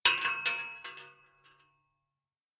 rifle_metal_2.ogg